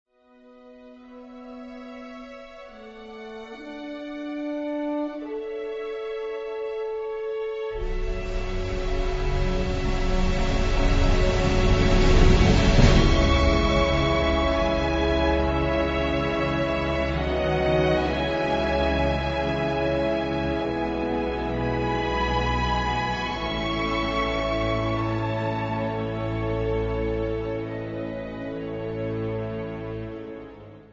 et ægte kraftværk af et symfonisk actionscore
er især domineret af blæsersektionerne